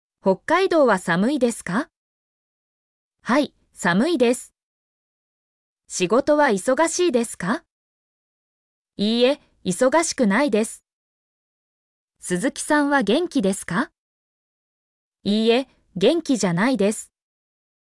mp3-output-ttsfreedotcom-9_ujIXzcZh.mp3